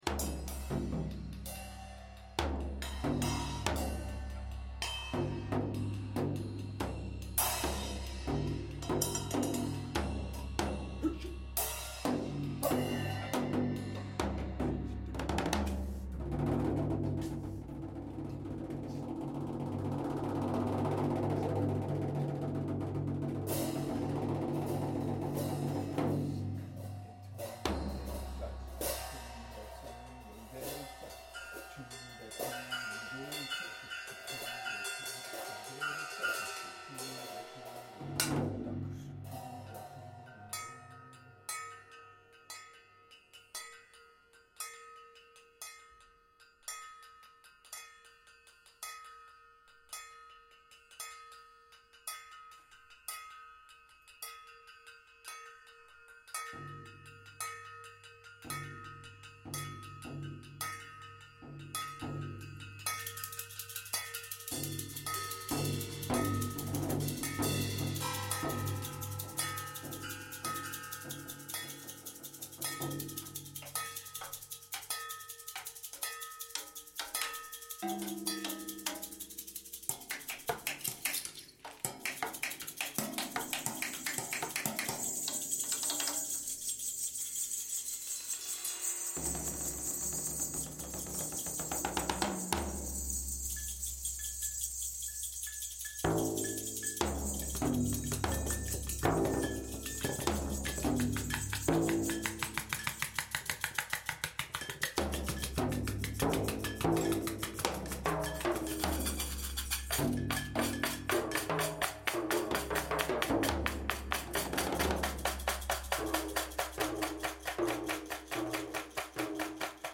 percussions